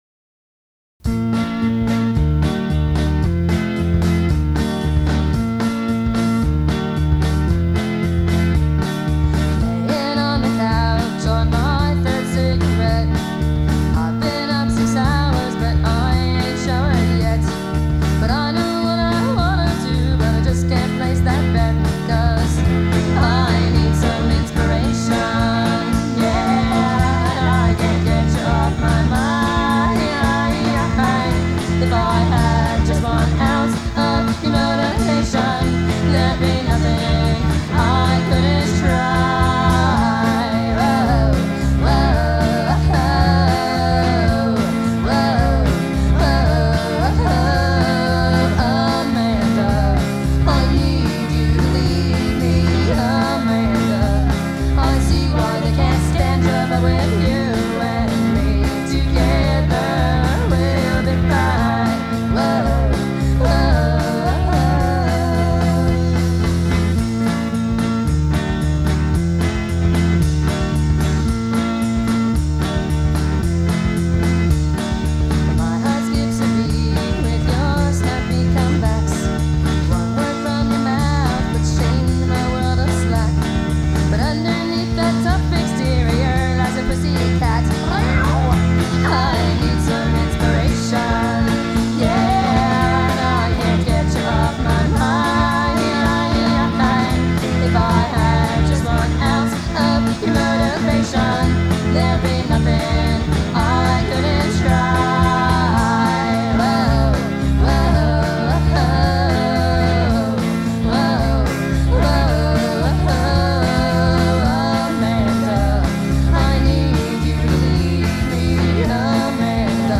organ
drums